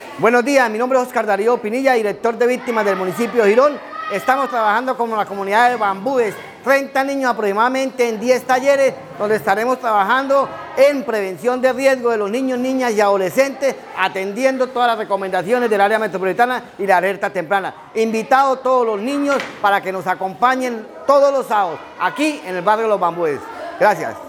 Óscar Pinilla - Director de Víctimas.mp3